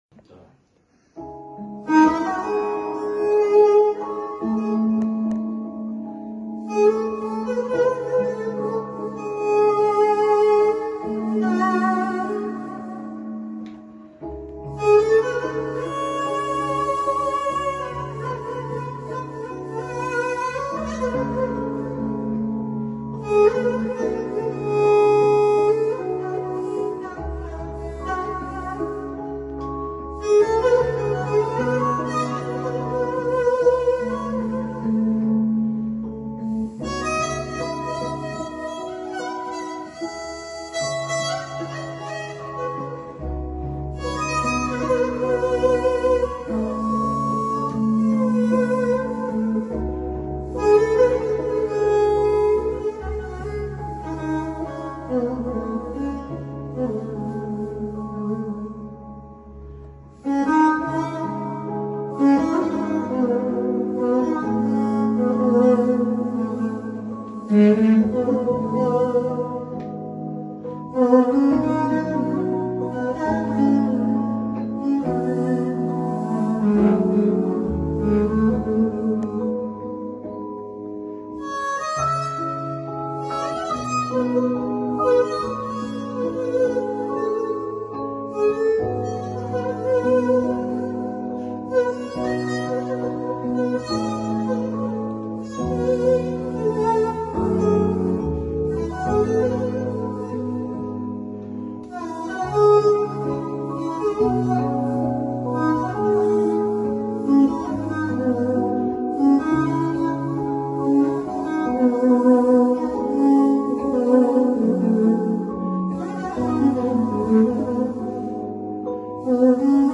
Canli Ifa